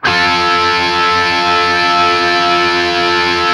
TRIAD F  L-R.wav